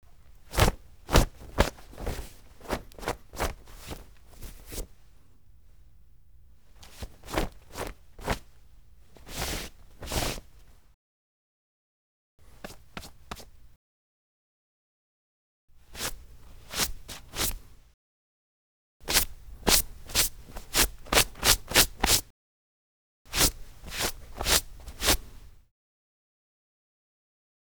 household
Cloth Brushing with Hands